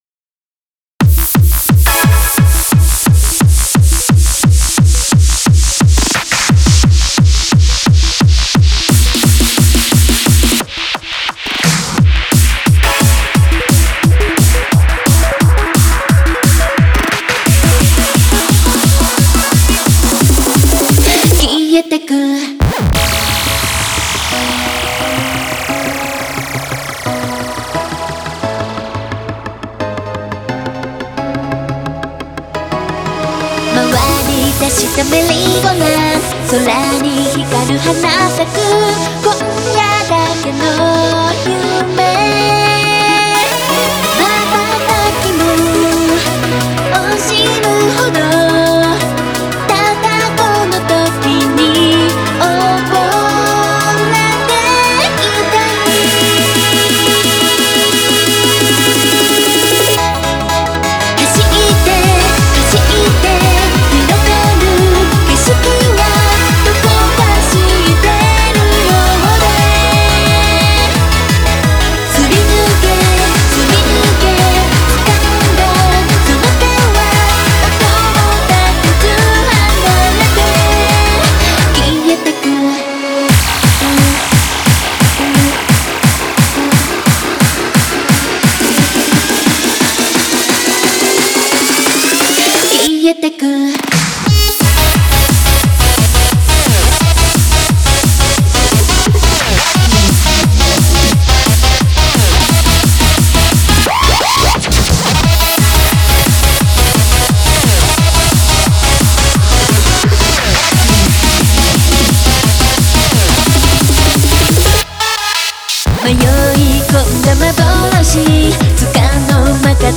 data/music/Japanese/J-Core